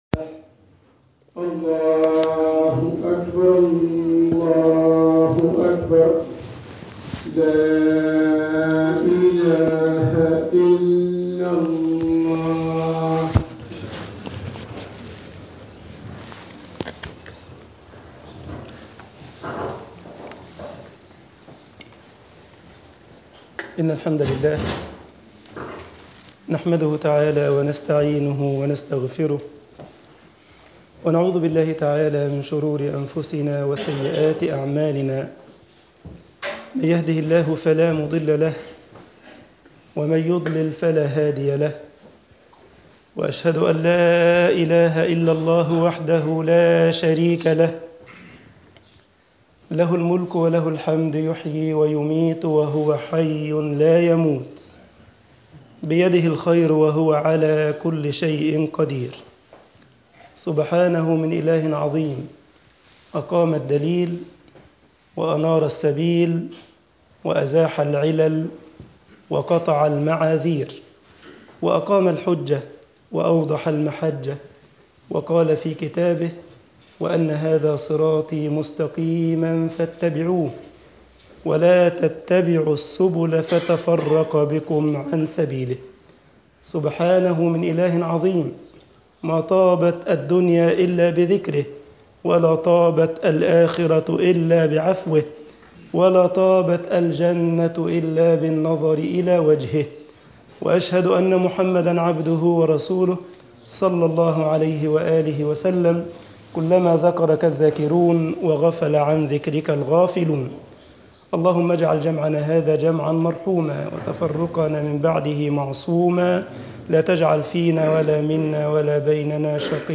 Freitagsgebet_al esmat min fetnat al-ilm7.mp3